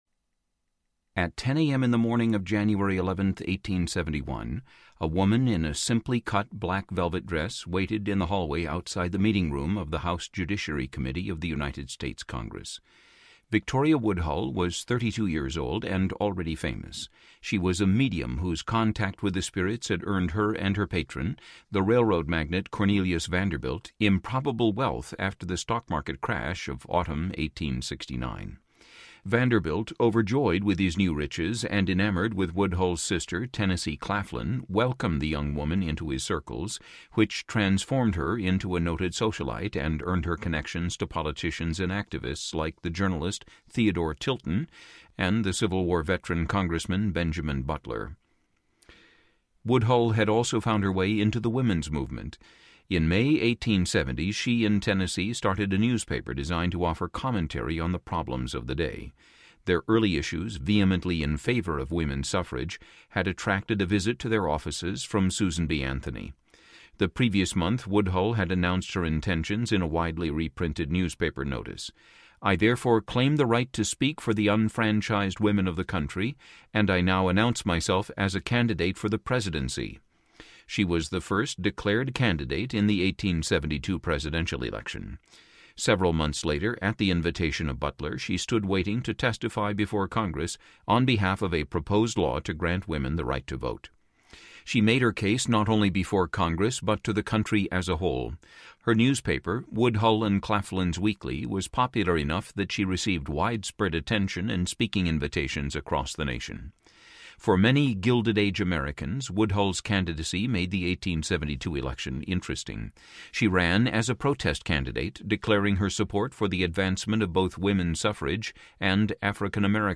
Christian Audiobook
Narrator
9.6 Hrs. – Unabridged